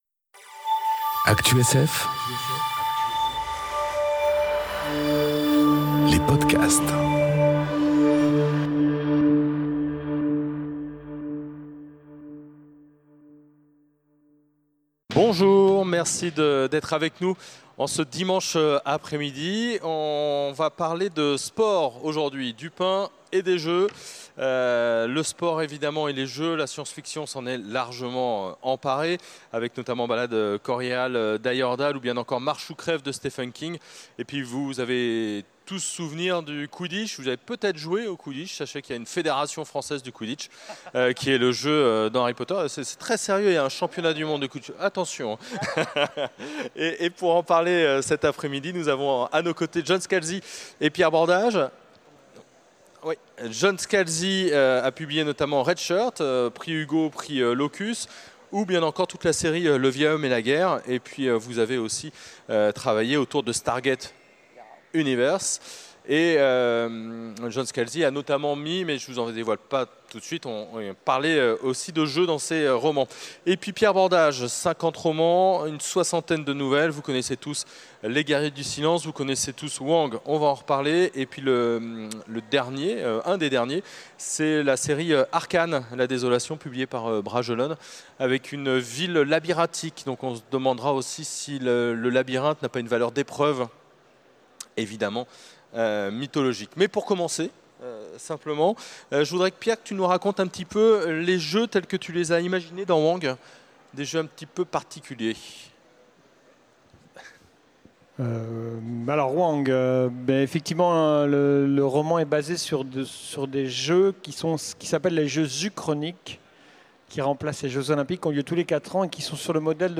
Conférence Du pain et des jeux enregistrée aux Utopiales 2018